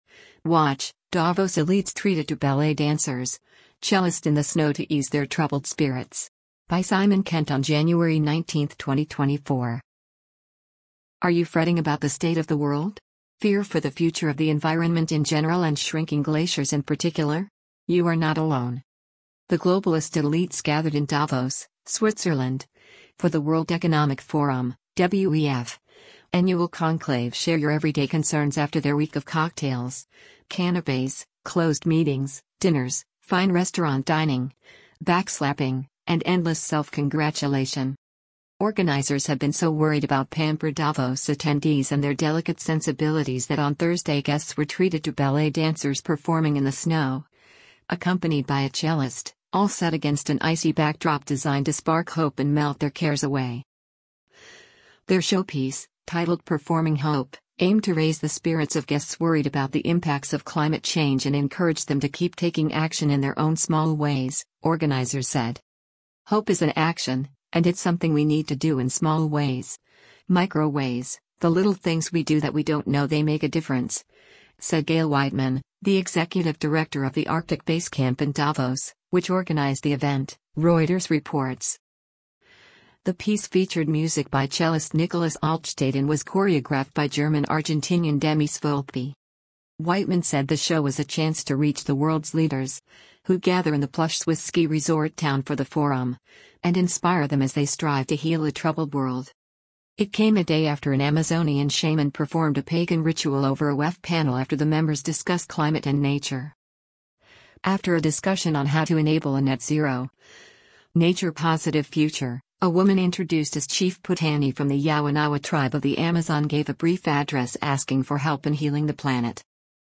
ballet show for the elites in Davos
Organizers have been so worried about pampered Davos attendees and their delicate sensibilities that on Thursday guests were treated to ballet dancers performing in the snow, accompanied by a cellist, all set against an icy backdrop designed to “spark hope” and melt their cares away.
The piece featured music by cellist Nicolas Altstaedt and was choreographed by German-Argentinian Demis Volpi.